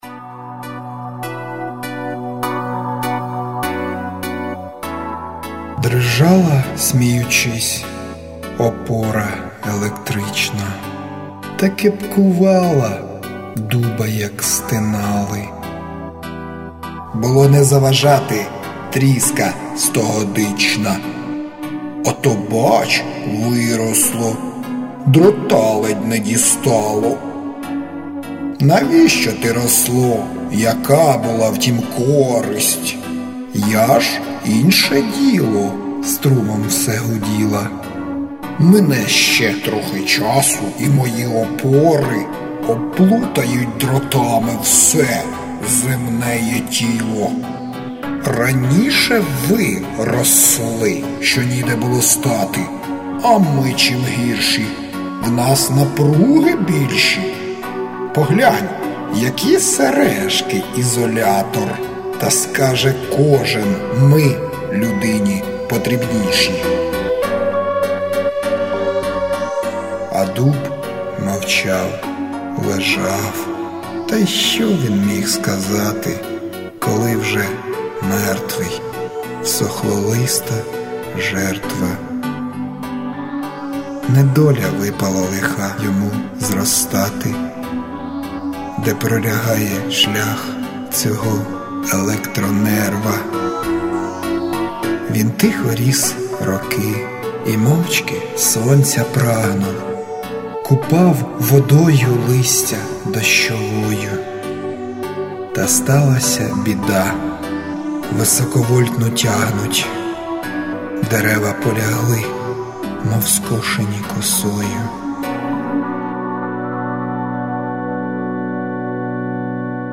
Переможні ролі (начитане)